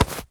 foley_object_grab_pickup_02.wav